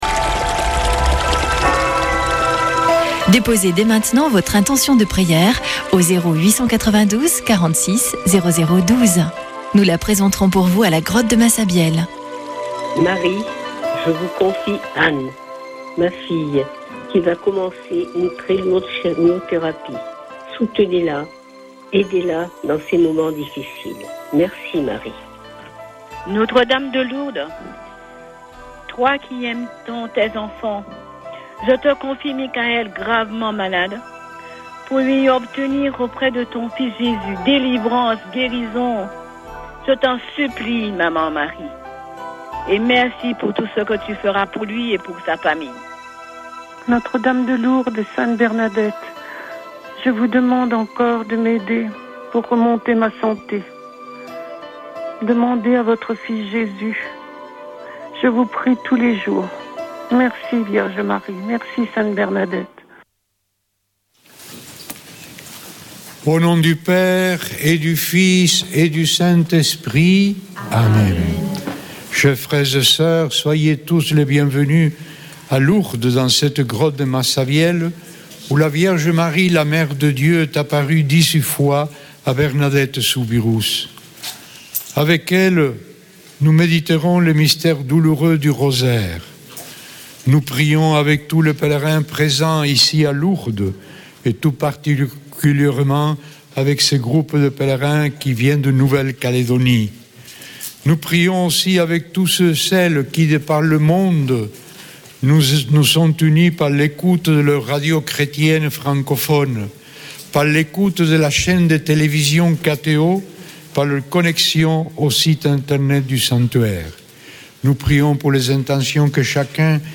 Accueil \ Emissions \ Foi \ Prière et Célébration \ Chapelet de Lourdes \ Chapelet de Lourdes du 25 nov.
Une émission présentée par Chapelains de Lourdes